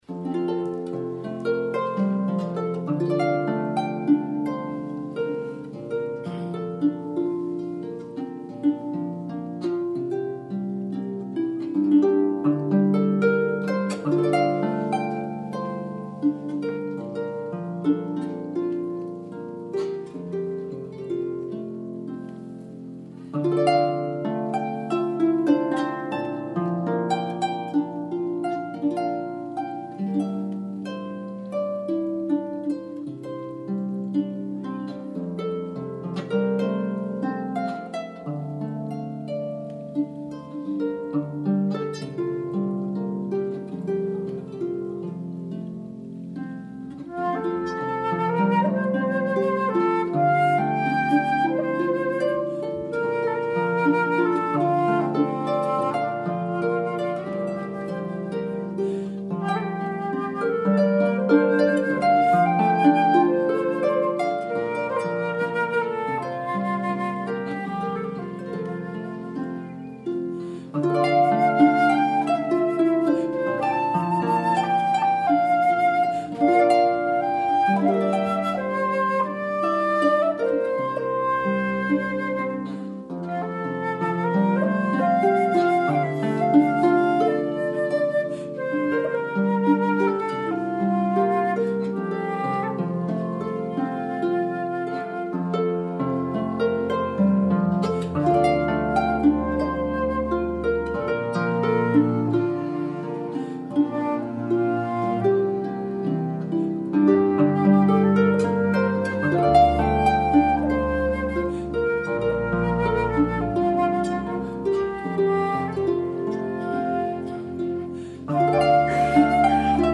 A song air